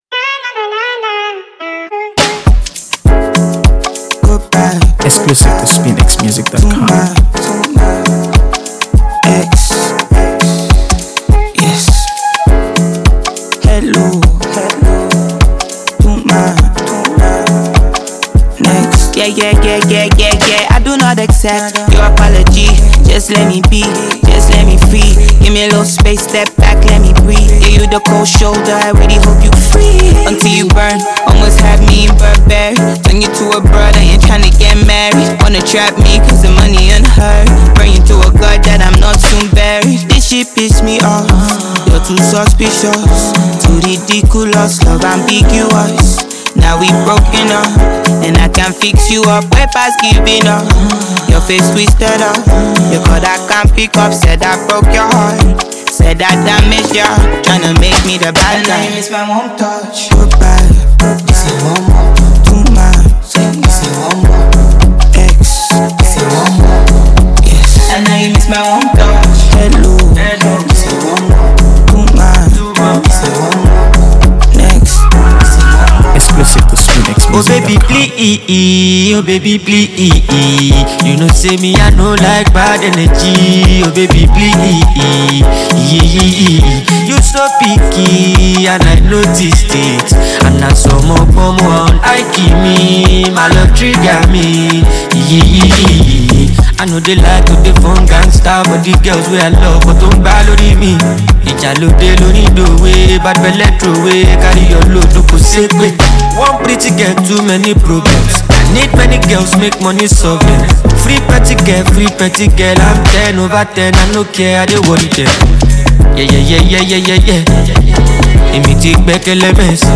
AfroBeats | AfroBeats songs
With its unforgettable melody and vibrant energy